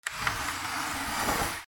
/ K｜フォーリー(開閉) / K05 ｜ドア(扉)
ふすま